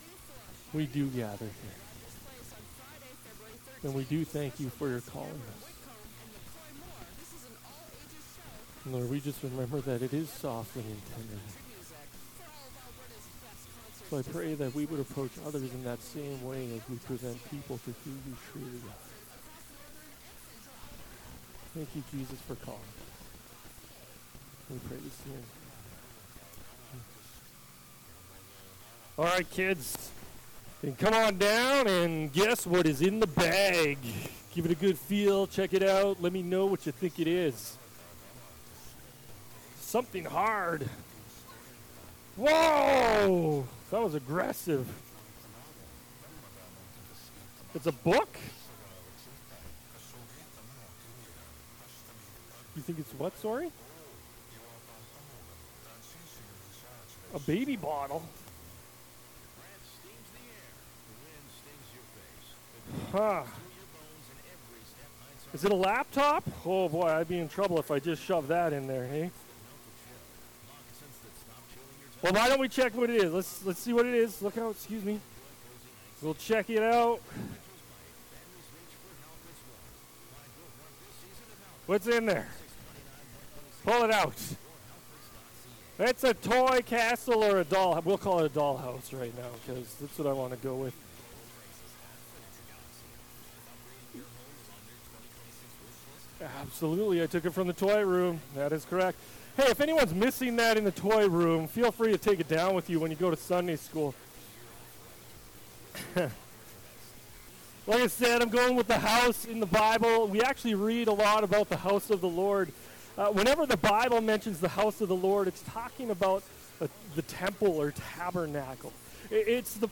Sermons | Muir Lake Community Alliance Church